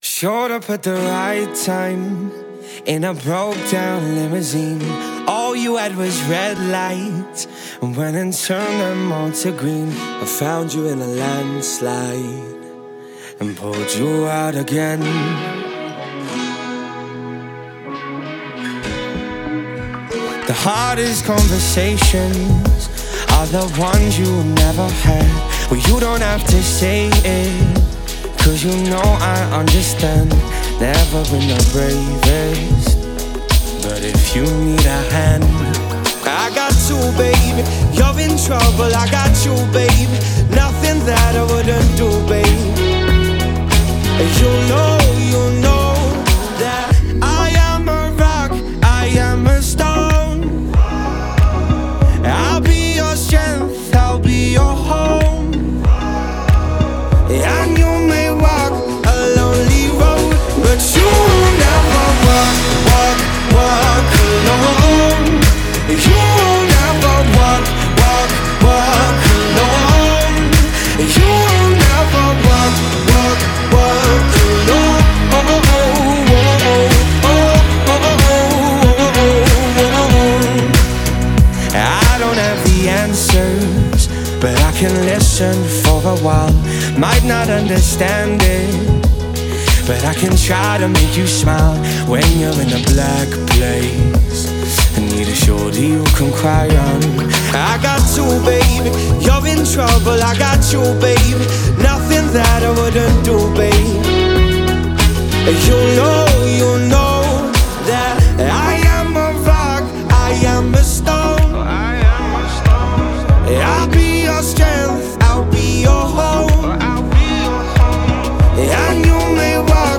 Genre: Electronic Dance